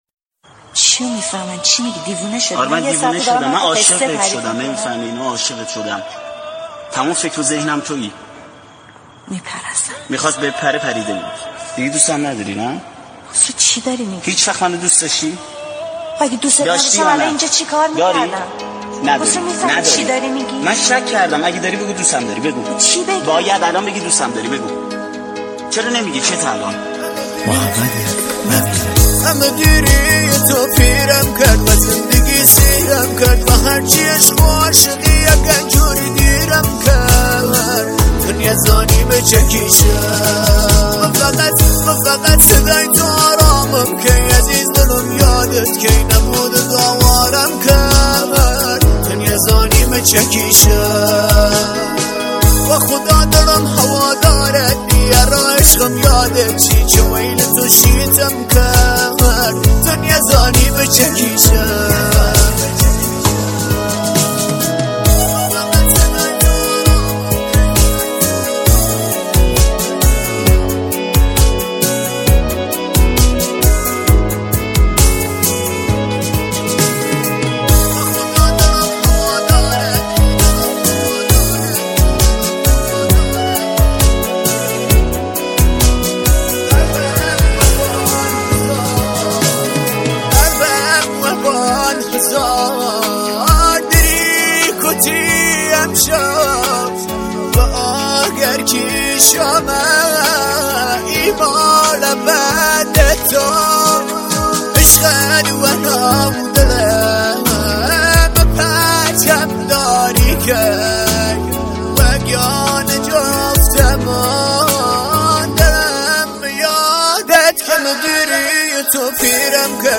🎹 آهنگ دیسلاو کردی مخصوص 🎹